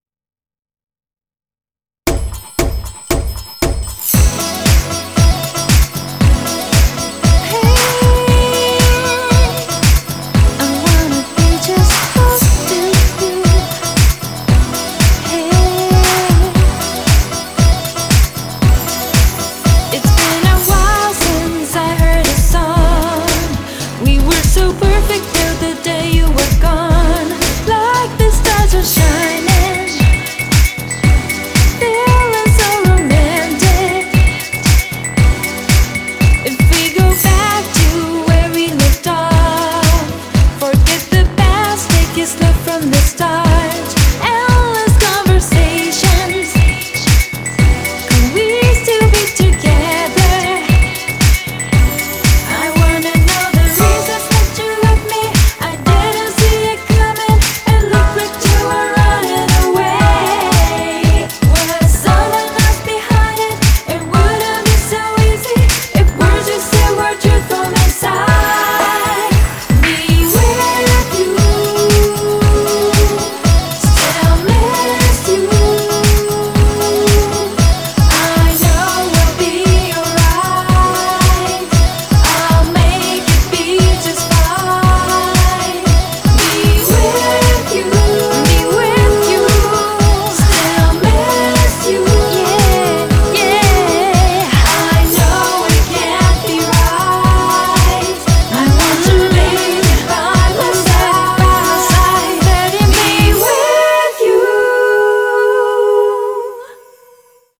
BPM116